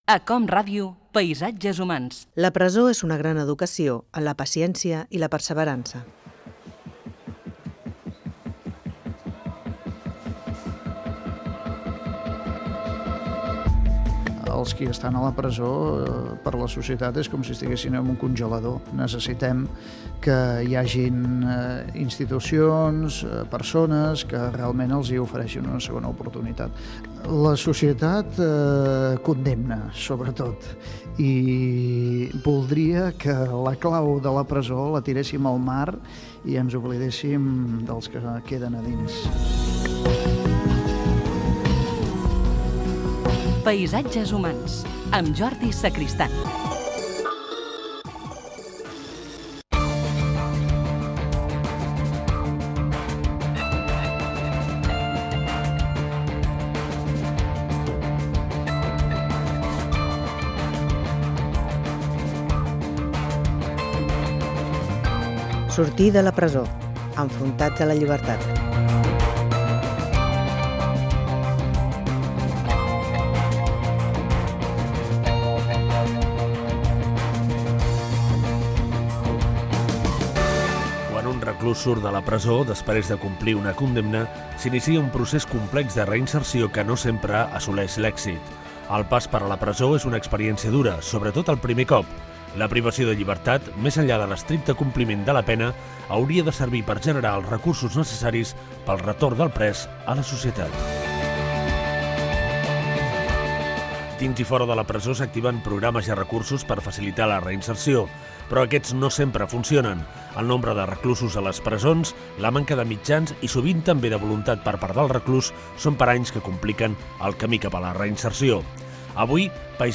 Divulgació
Fragment extret de l'arxiu sonor de COM Ràdio.